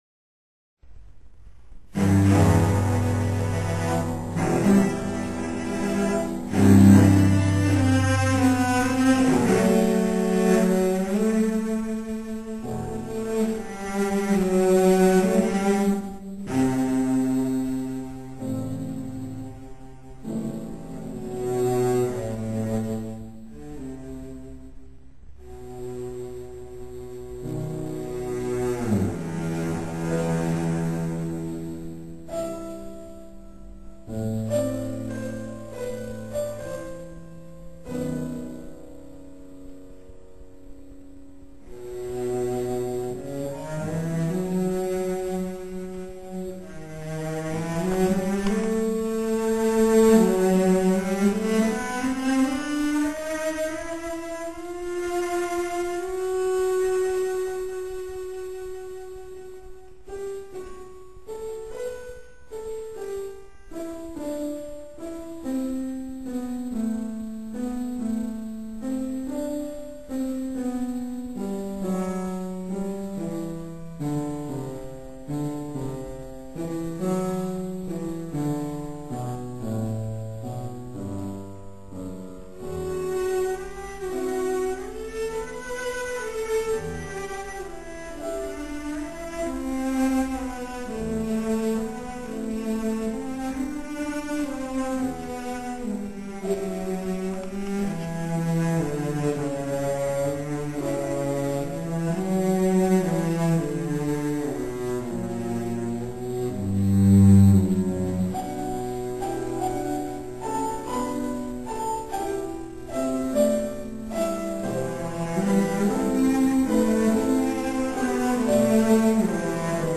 20. komorní koncert na radnici v Modřicích
violoncello
- ukázkové amatérské nahrávky, v ročence CD Modřice 2006 doplněno: